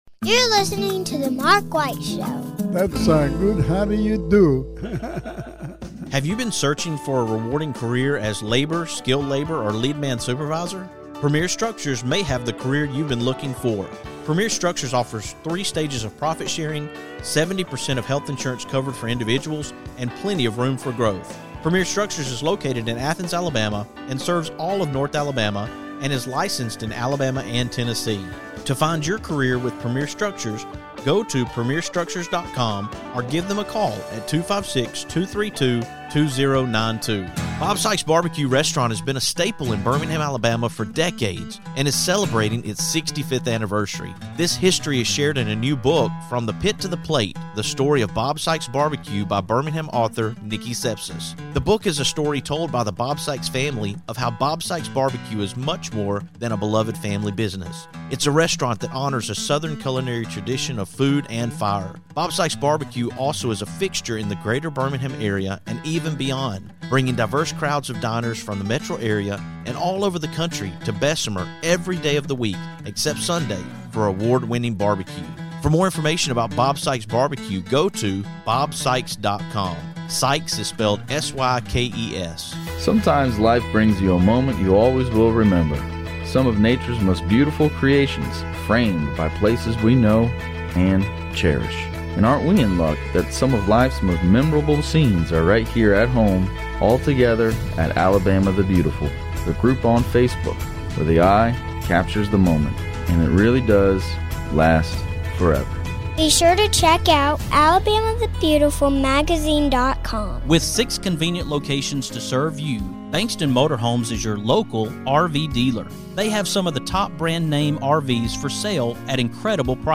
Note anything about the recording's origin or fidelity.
She agreed and we met at the Vestavia Hills Library for the interview.